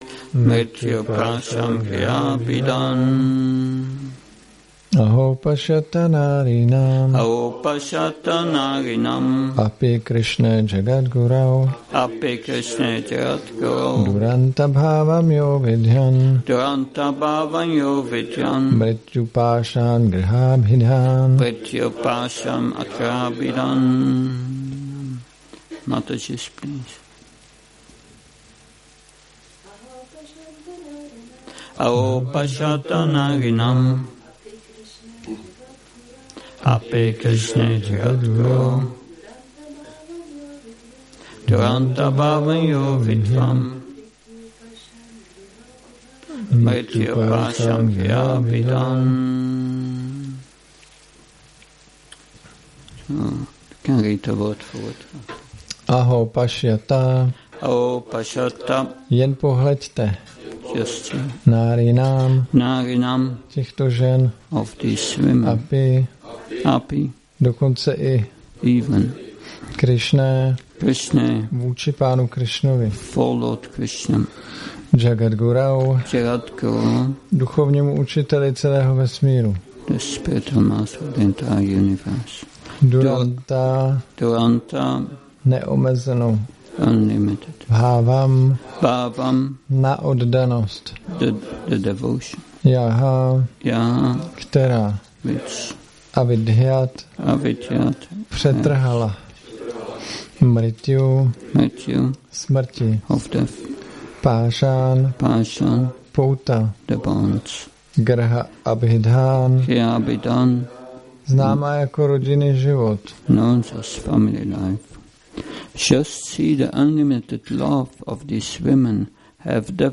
Přednáška SB-10.23.42